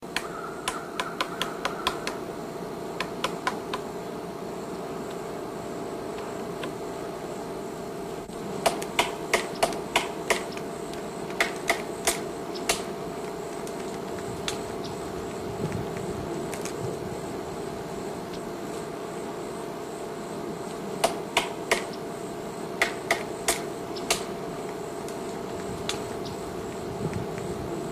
딱따구리과(Picidae)의 딱따구리가 나무를 쪼는 소리 (배경 소리 포함). 종/위치는 미상이며, 아마도 미국 본토에서 녹음된 것으로 추정